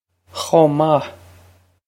Pronunciation for how to say
khoh mah
This is an approximate phonetic pronunciation of the phrase.